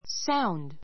sound 1 小 A2 sáund サ ウン ド 名詞 音 , 響 ひび き 類似語 noise （騒音 そうおん ） make a sound make a sound 音を立てる Sound travels through the air.